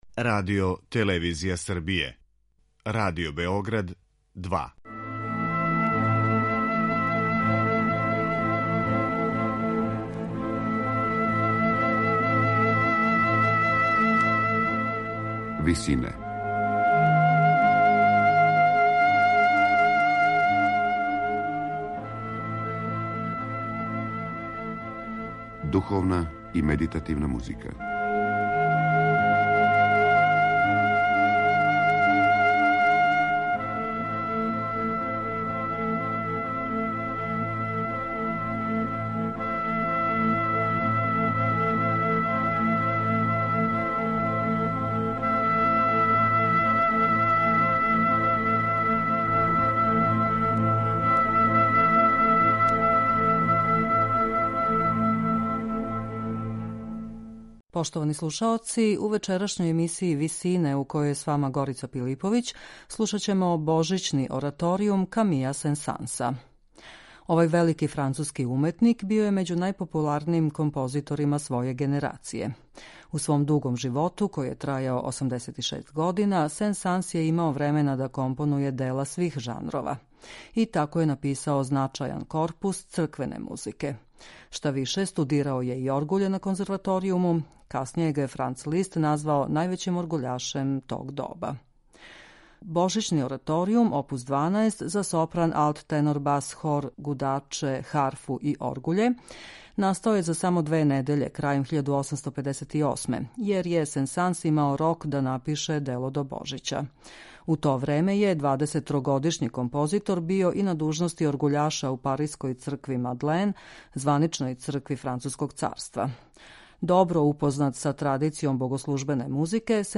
Па ипак, композиција која се састоји од десет нумера, показује необичан распон извођачких пракси - од соло речитатива до оперских епизода.